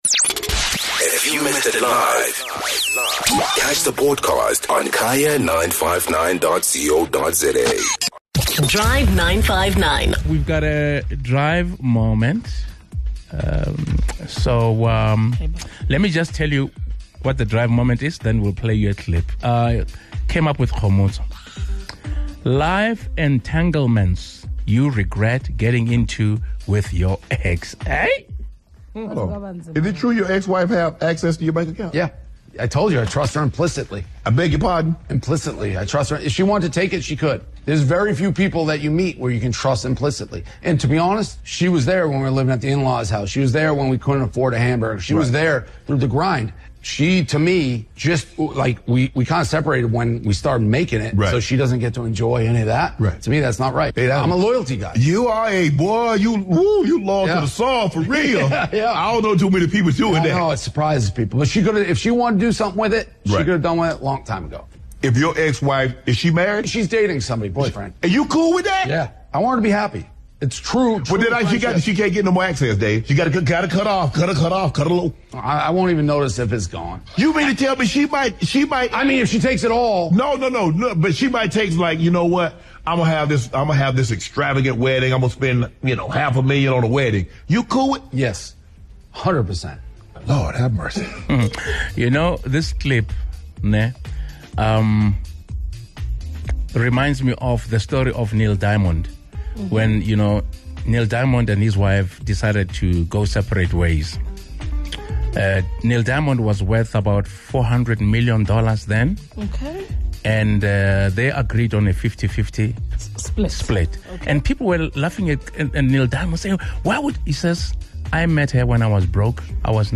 Our Drive 959 listeners shared their life entanglements with their exes.